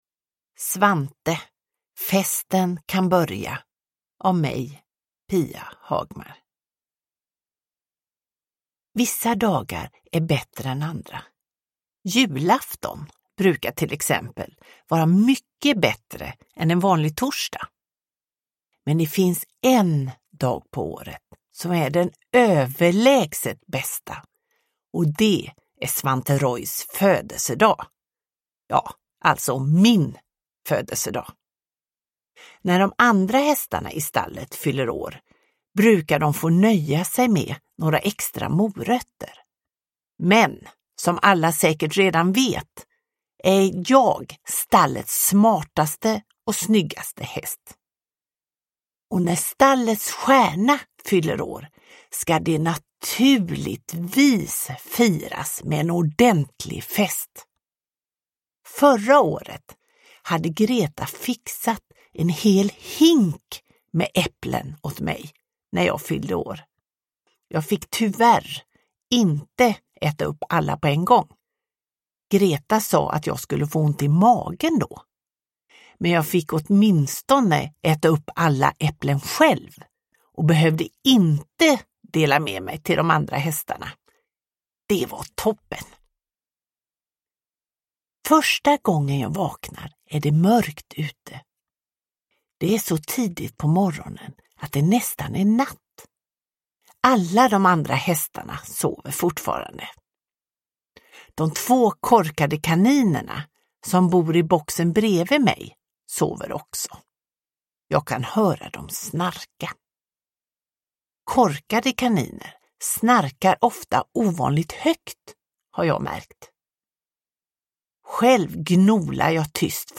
Festen kan börja – Ljudbok – Laddas ner